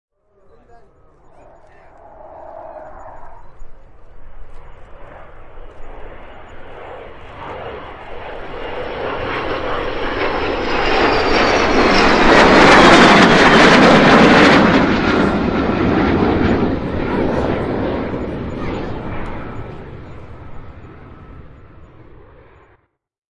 达索航空公司 "阵风 "战斗机 " 阵风 "出租车
描述：Marantz PMD 661 MK II便携式录音机音响Technica AT 4053B麦克风
标签： 喷射 现场记录 飞机
声道立体声